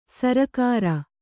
♪ sarakāra